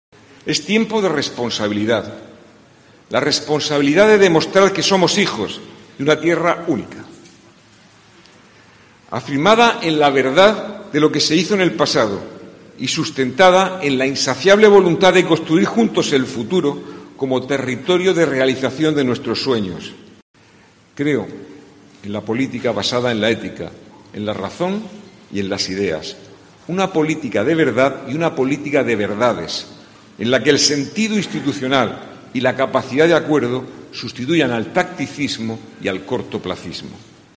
El acto de constitución del Ayuntamiento de Murcia ha tenido lugar esta mañana en el Salón de Plenos, donde han jurado y prometido sus cargos los 29 concejales que conformarán la Corporación Municipal durante el próximo mandato.
José Ballesta, alcalde de Murcia